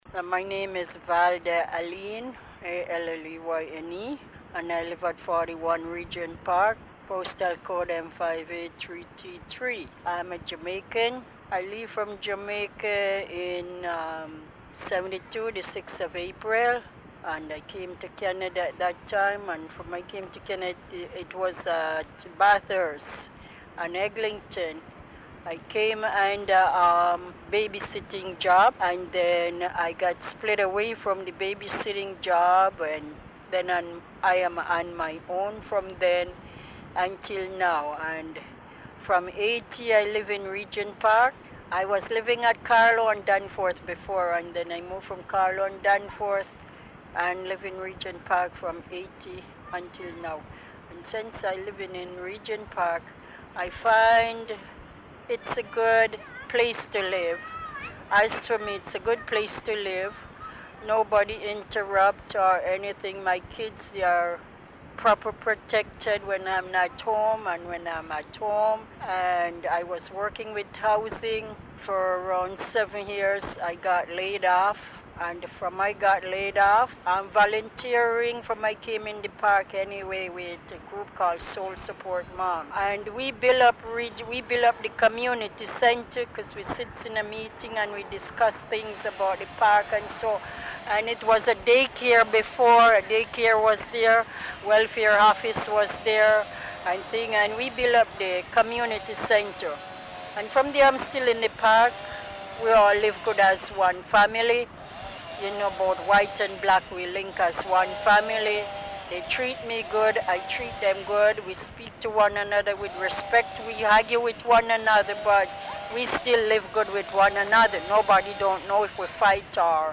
Journey Home is a story-telling project that explores the journey from homeland to Canada, through the voices of Regent Park residents.